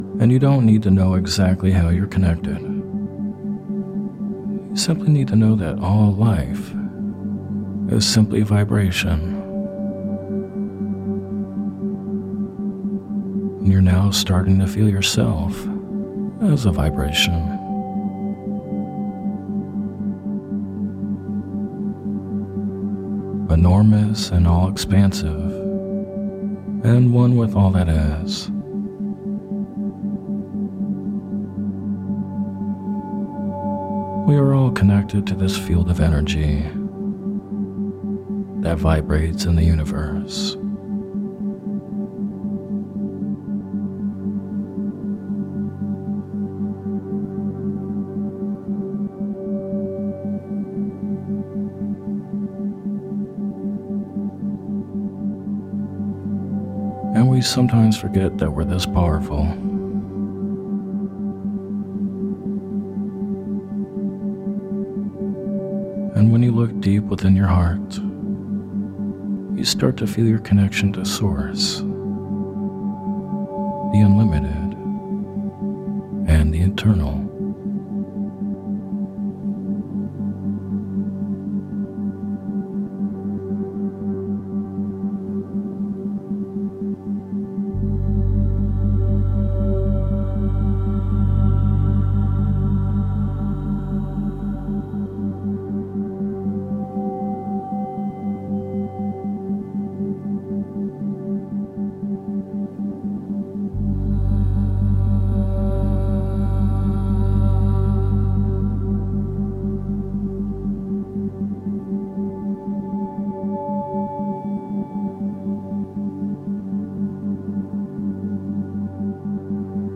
Guided Meditation for Connecting To Source Energy and your Higher Self With Isochronic Tones
This guided meditation (or hypnosis audio) was created to help you connect with source energy or Higher Self. The music for this was tuned to 432hz. **This version includes isochronic tones.